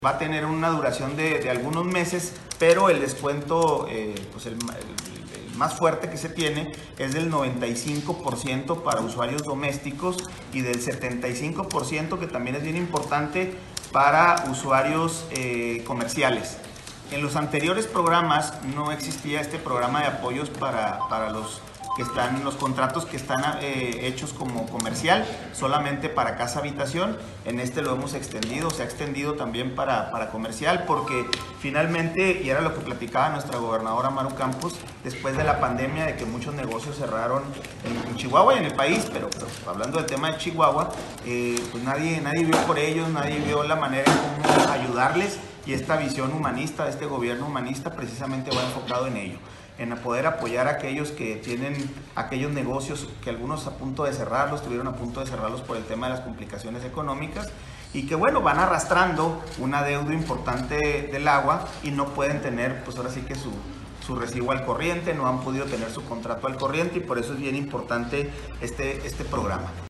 AUDIO: ALAN FALOMIR, DIRECTOR DE LA JUNTA MUNICIPAL DE AGUA Y SANEAMIENTO (JMAS)